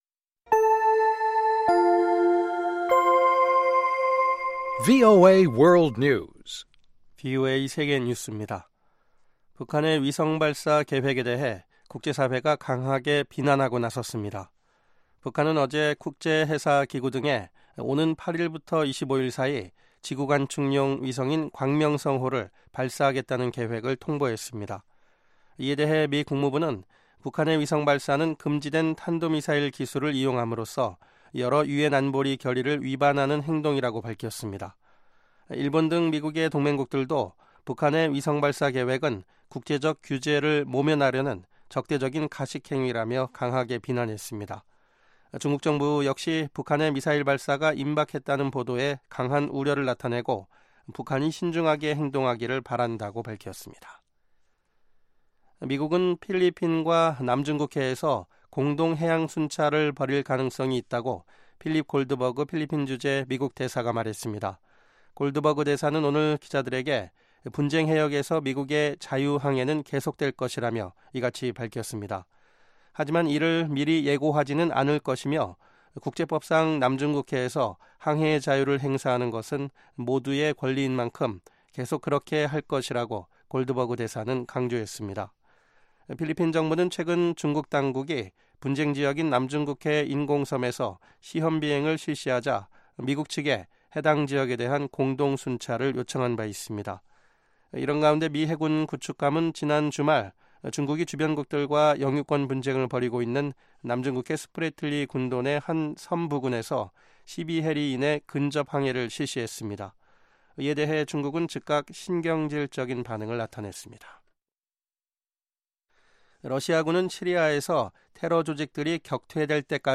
VOA 한국어 방송의 간판 뉴스 프로그램 '뉴스 투데이' 3부입니다. 한반도 시간 매일 오후 11:00 부터 자정 까지, 평양시 오후 10:30 부터 11:30 까지 방송됩니다.